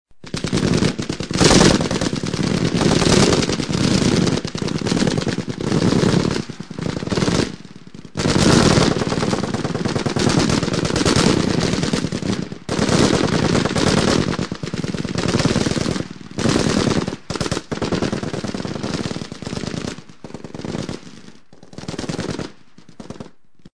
ARMAS AMETRALLADORA FUSIL
Descargar EFECTO DE SONIDO DE AMBIENTE ARMAS AMETRALLADORA FUSIL - Tono móvil
armas__ametralladora_fusil.mp3